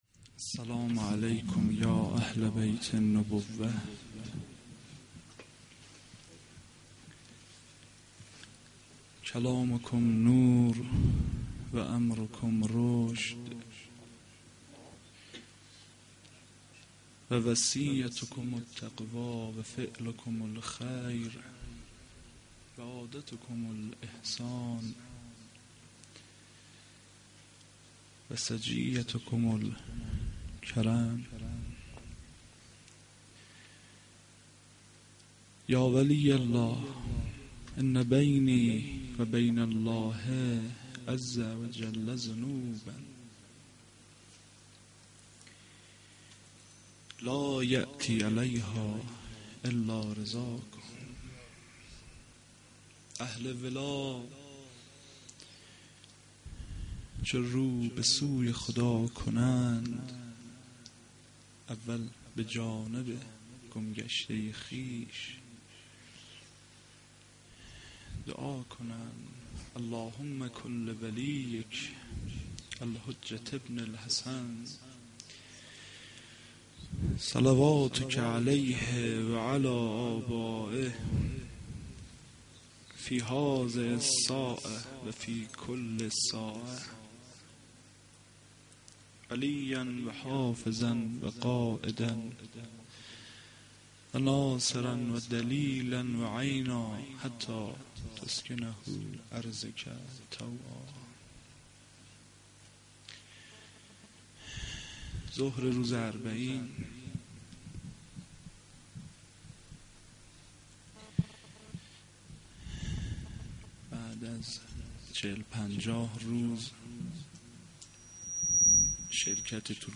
Arbaeen92-Rozeh.mp3